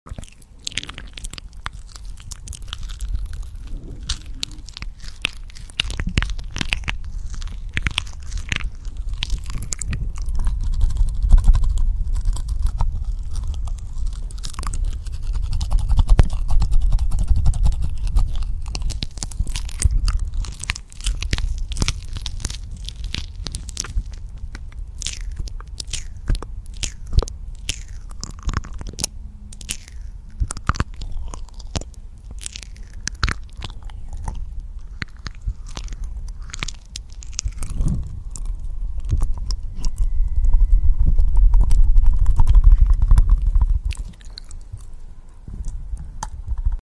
Slow Mouth Sounds Asmr 🌸 sound effects free download